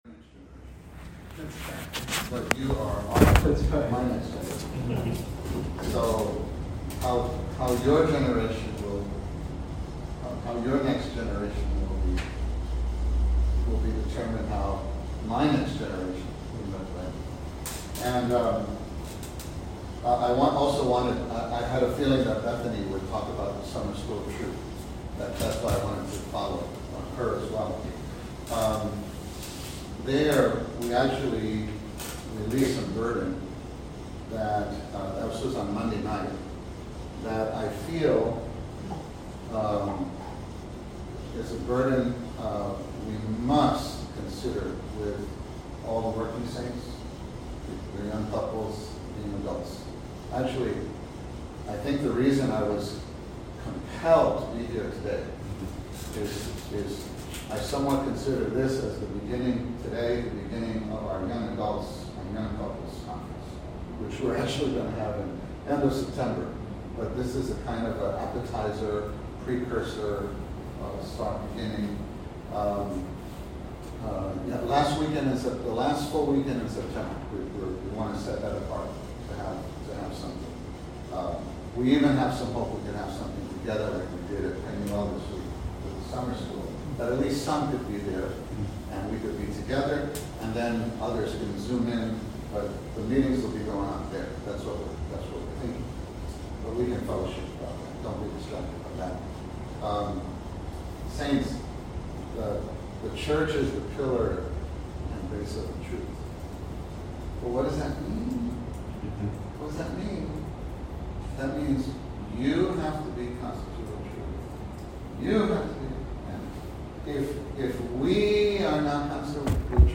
The following was recorded during the Lord’s Day meeting at Hall 2 – 34th Street on August 8, 2021.